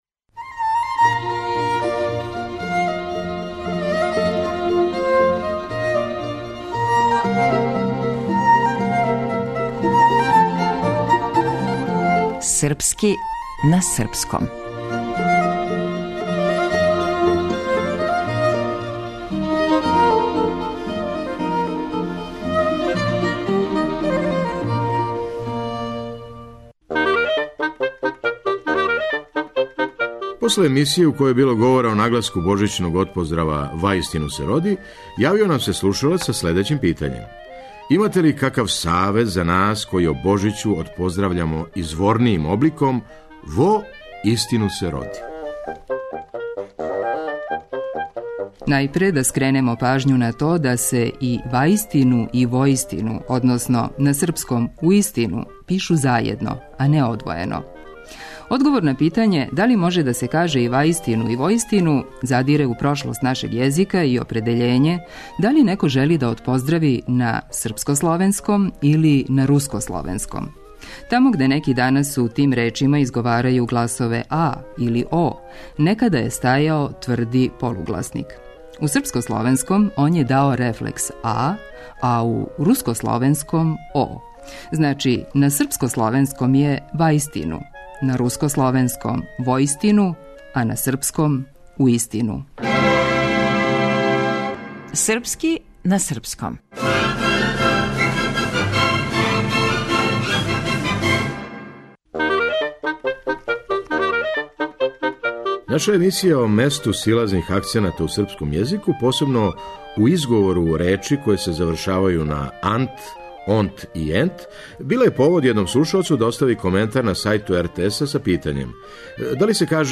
Наша емисија о месту силазних акцената у српском језику, посебно у изговору речи које се завршавају на –ант, -онт и –ент, била је повод једном слушаоцу да остави коментар на сајту РТС-а са питањем – да ли се каже "био сам код аСИСтента или био сам код асисТЕНта?"
Драмски уметник